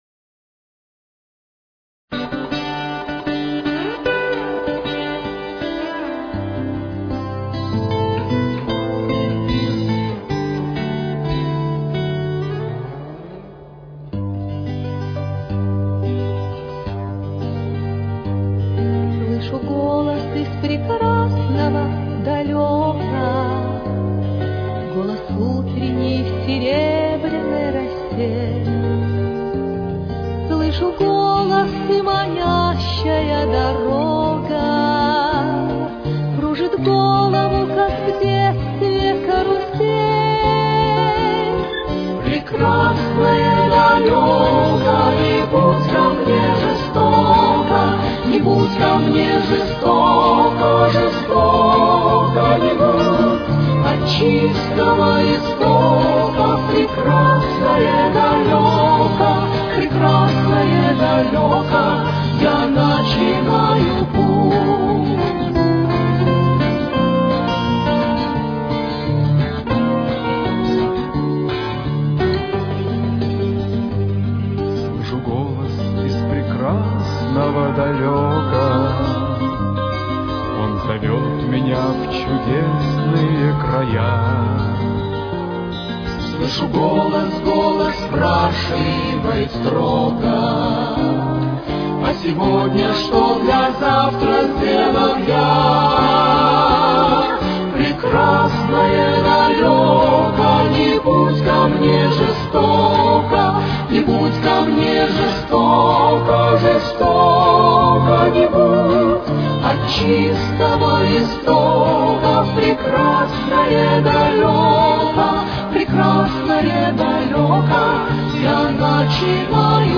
с очень низким качеством (16 – 32 кБит/с)
Тональность: Соль минор. Темп: 87.